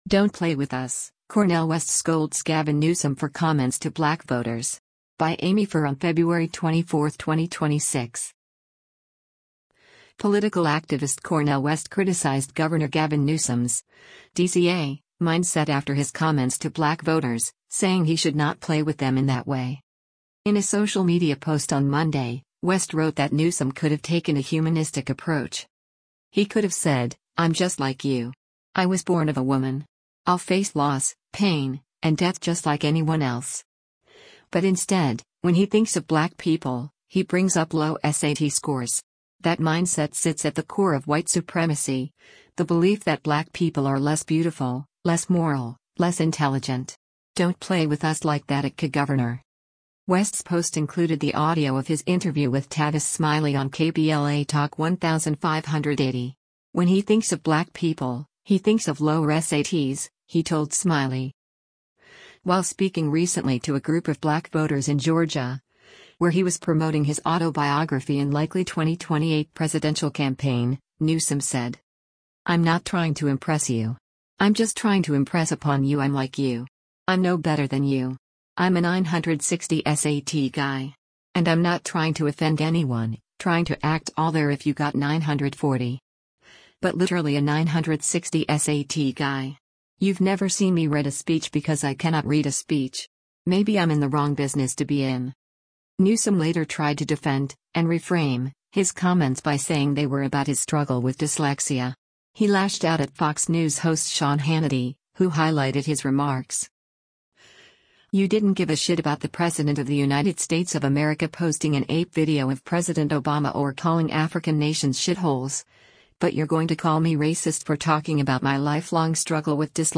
West’s post included the audio of his interview with Tavis Smiley on KBLA Talk 1580.